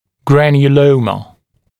[ˌgrænjuˈləumə][ˌгрэнйуˈлоумэ]гранулема